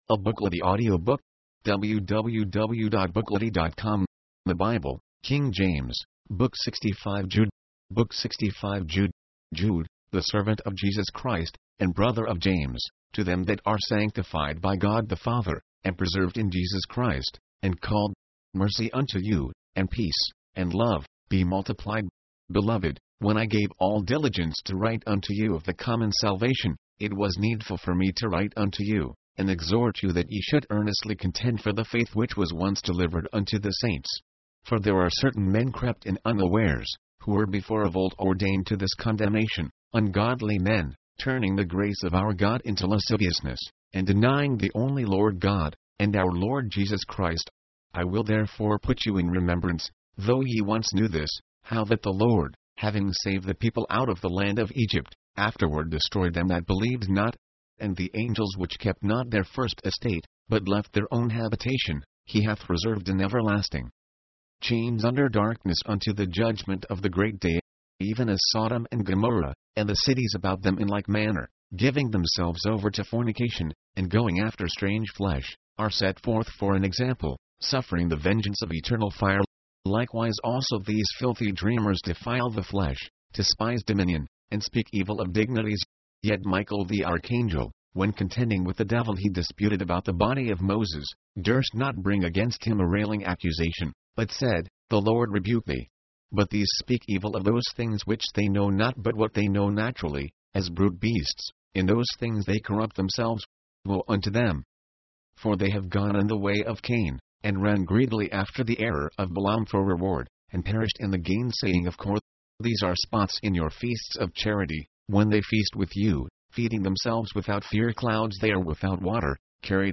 The author is traditionally associated with Jude, the brother of the Lord. mp3, audiobook, audio, book